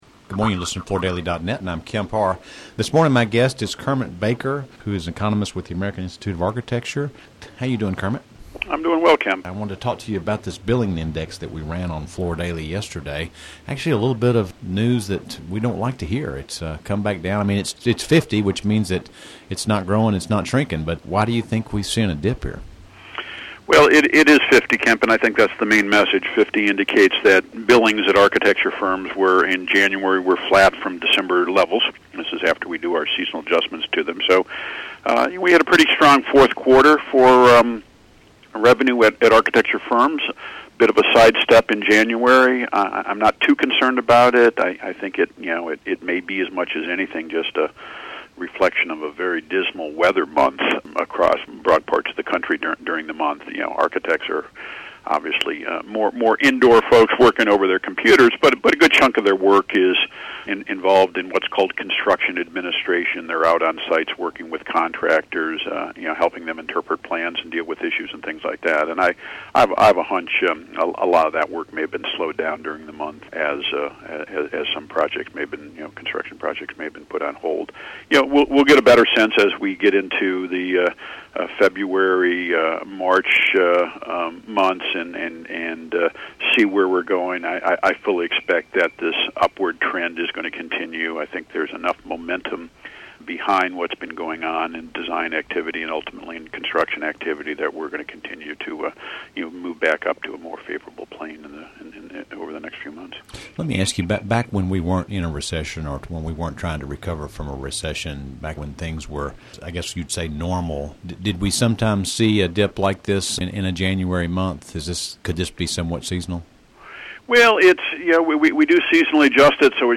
Listen to the interview to hear more details about this and his view of the residential remodel outlook for 2011.